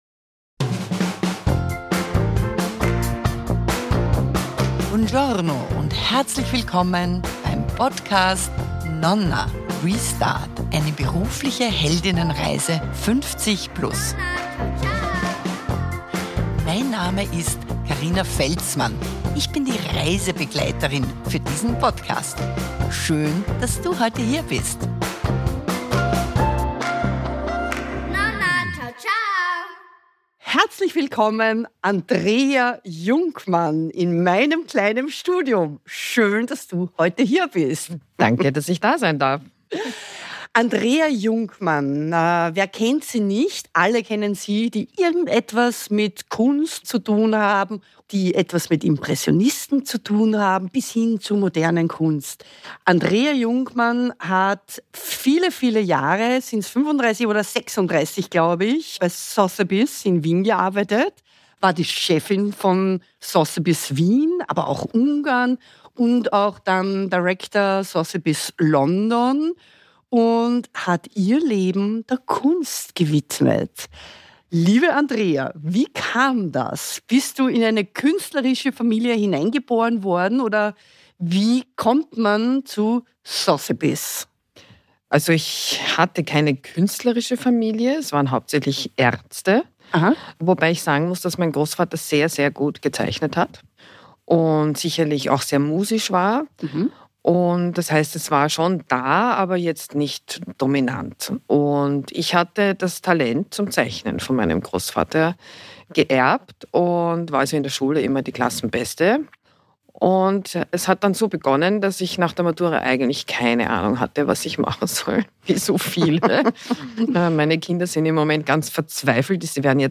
Podcasterin und Coachin